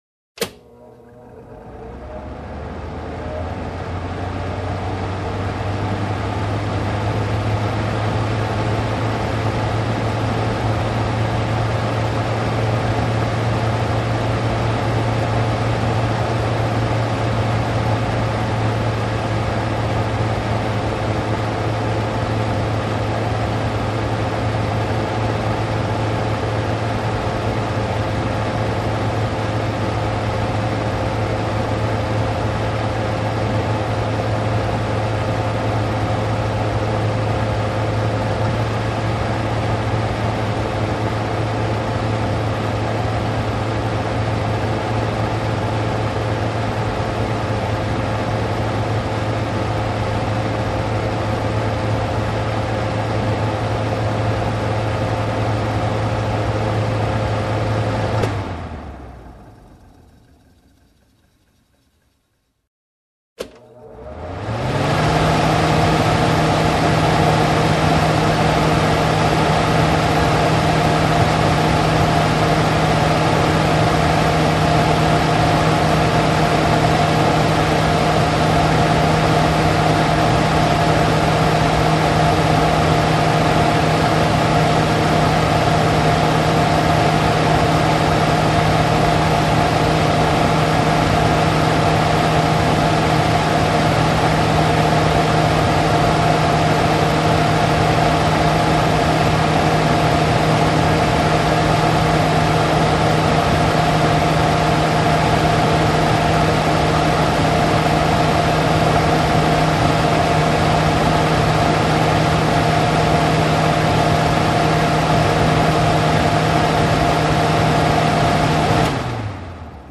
Páraelszívó | hanghatás .mp3 | Letöltés ingyen.
Páraelszívó: